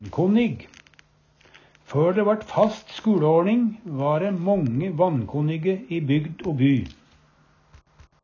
vankonnig - Numedalsmål (en-US)
Høyr på uttala Ordklasse: Adjektiv Kategori: Kropp, helse, slekt (mennesket) Attende til søk